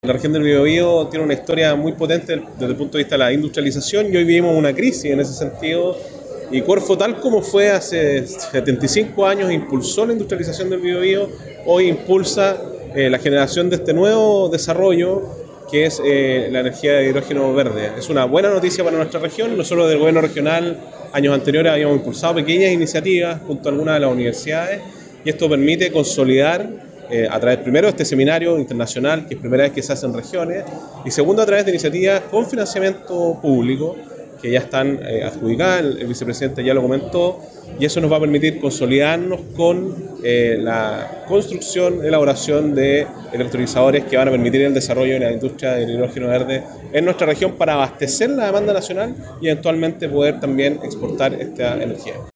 Este martes se realizó en Biobío el Green Hydrogen Summit Chile LAC 2025, instancia donde se abordaron las oportunidades y desafíos del desarrollo energético regional, por primera vez fuera de Santiago, considerando el rol estratégico de la industria local.